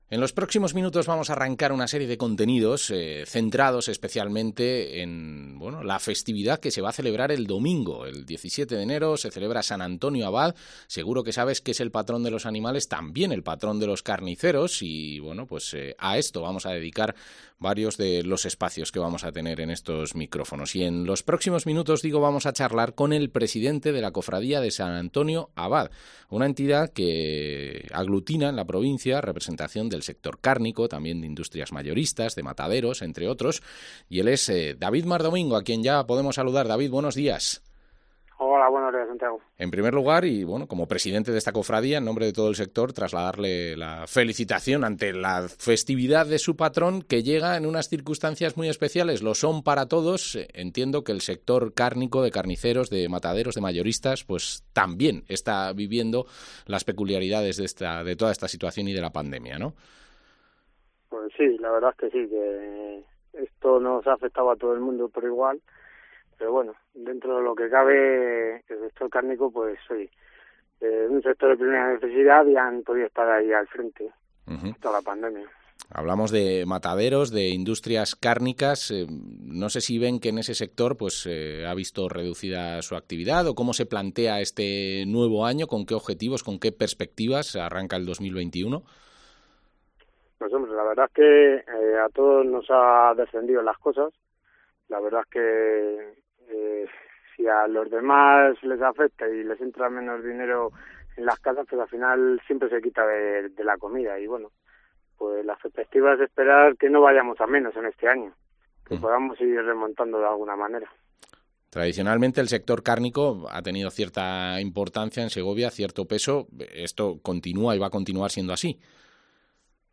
AgroCOPE Segovia Entrevista